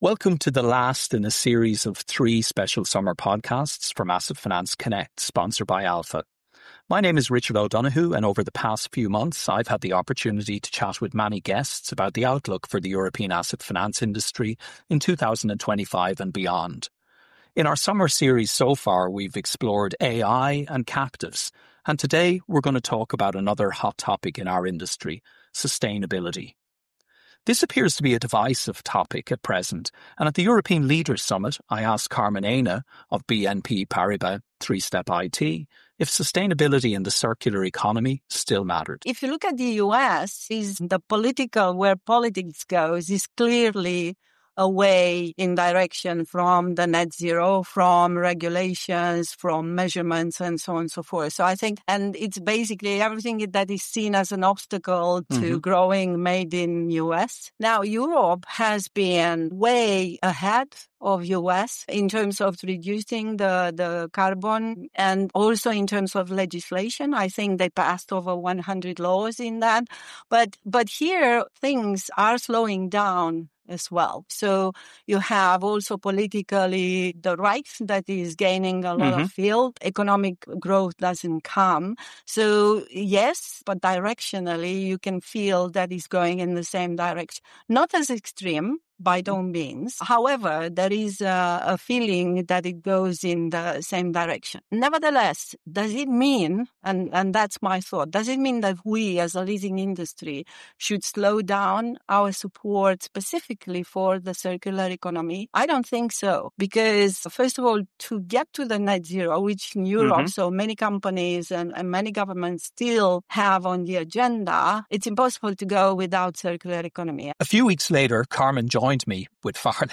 Each episode of our podcast will feature insightful interviews with Europe’s top leaders in equipment finance. The discussions will centre on the opportunities and challenges that lie ahead for European equipment captives, banks, and independent finance providers in 2025, offering valuable perspectives to navigate the evolving industry landscape.